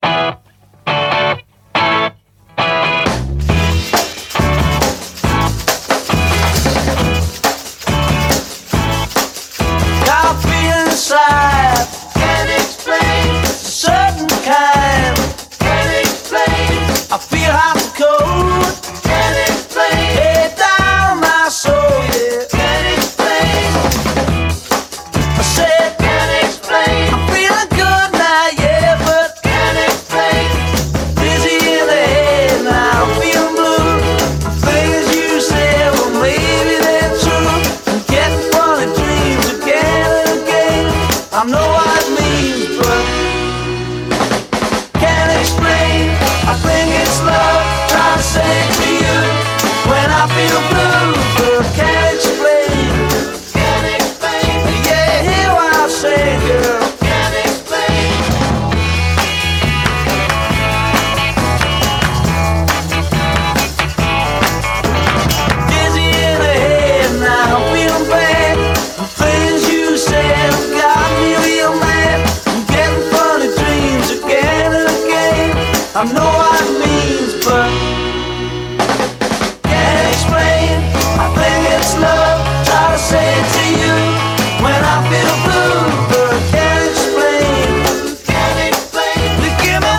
ROCK / 60'S / MOD / BRITISH R&B / BRITISH BEAT
BRITISH R&Bなレア・トラックを収録！